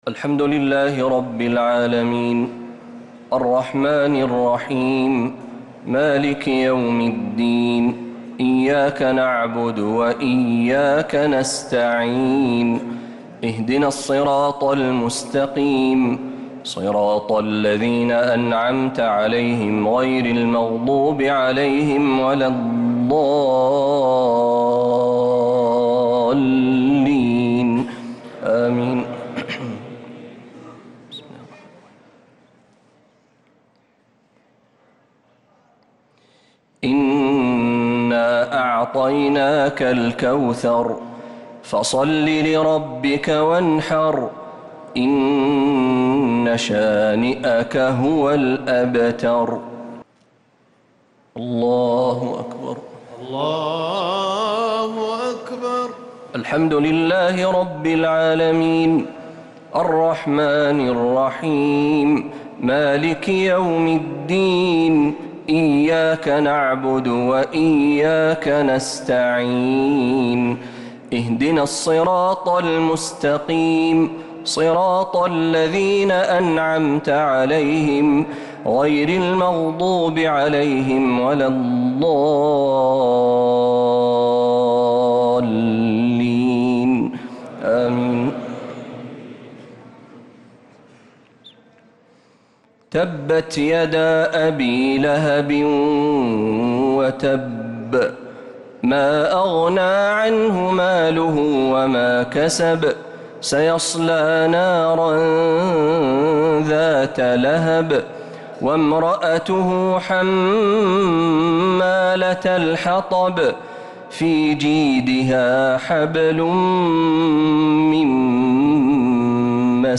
الشفع و الوتر ليلة 15 رمضان 1446هـ | Witr 15th night Ramadan 1446H > تراويح الحرم النبوي عام 1446 🕌 > التراويح - تلاوات الحرمين